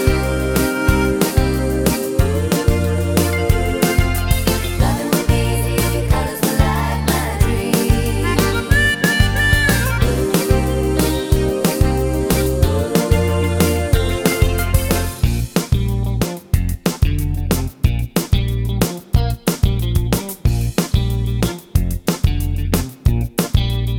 no intro or solo harmonica Pop (1980s) 3:57 Buy £1.50